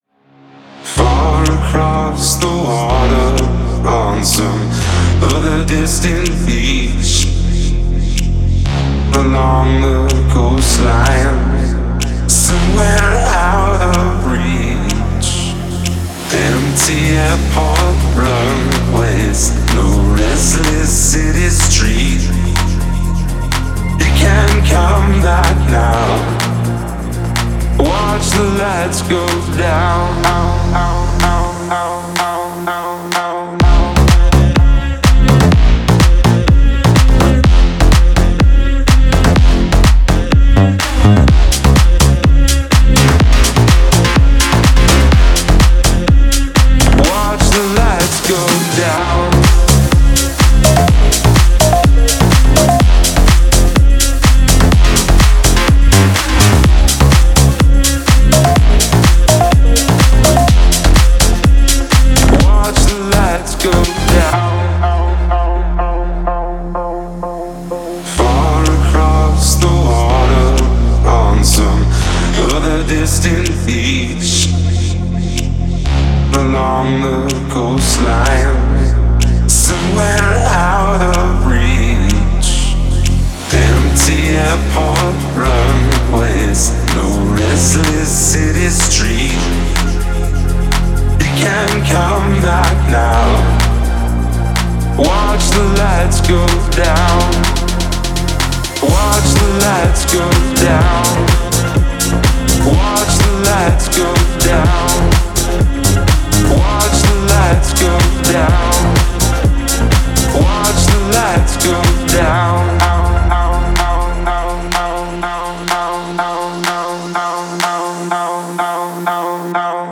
Стиль: Dance / Electronic / House / Pop